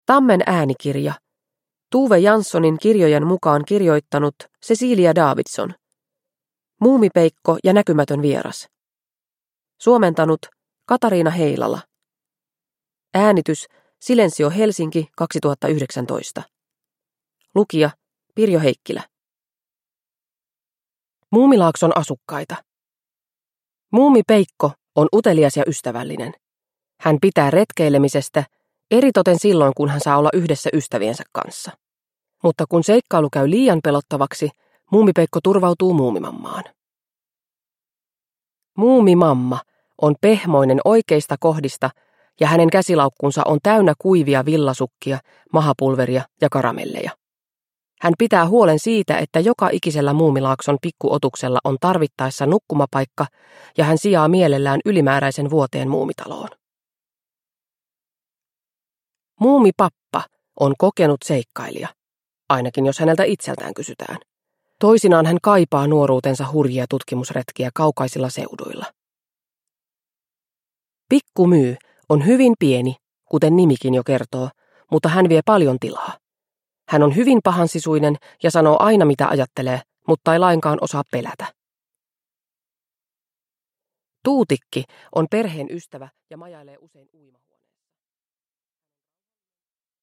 Muumipeikko ja näkymätön vieras – Ljudbok – Laddas ner